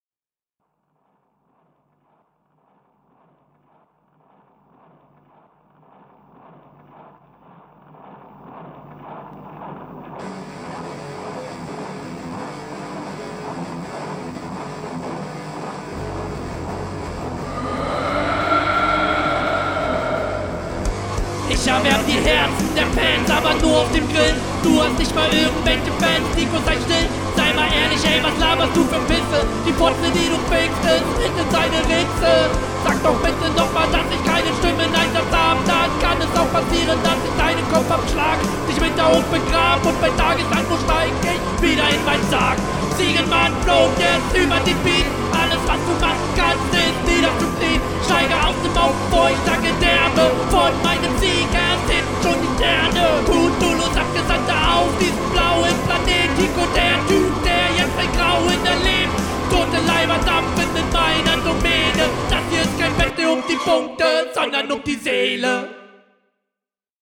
Uh was Rockiges.
Man versteh fast nix.